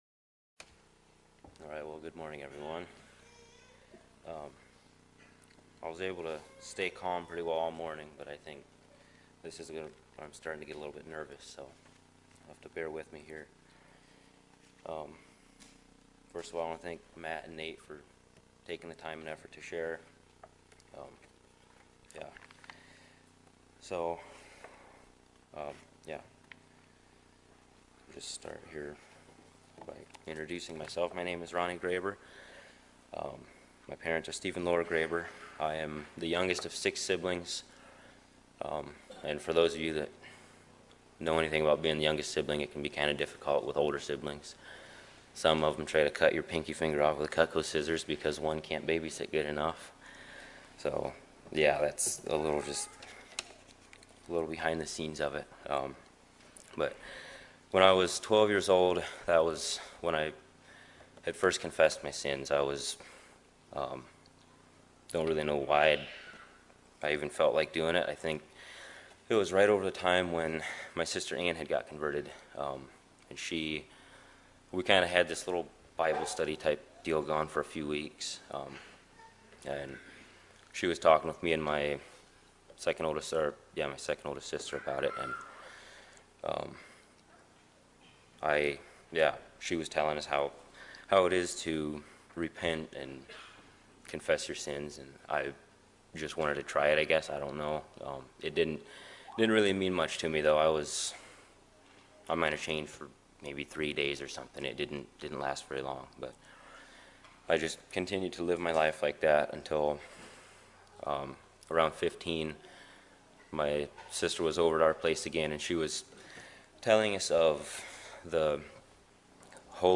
Baptismal Testimony